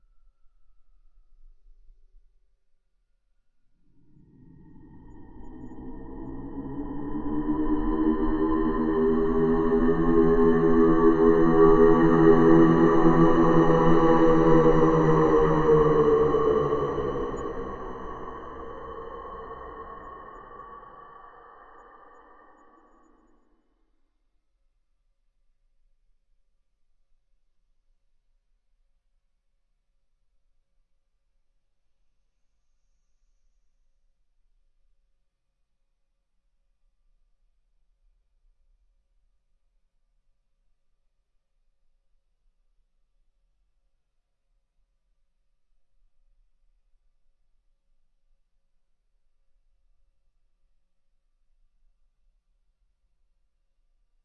令人毛骨悚然的呻吟声
描述：我记录了这个我的自我，并使用大胆的paulstretch使它听起来很怪异，享受
Tag: 令人毛骨悚然 恐怖 呻吟